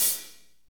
HAT F R H21L.wav